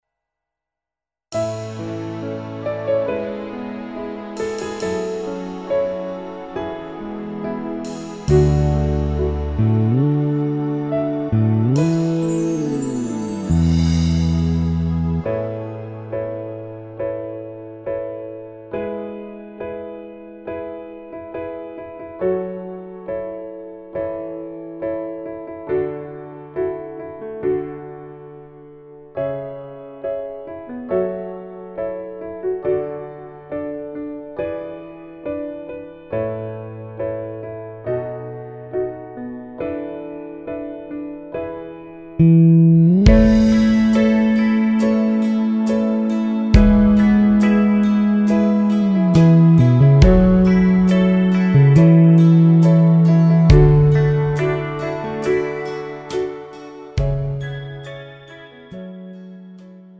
음정 (여자키)
장르 가요 구분 Pro MR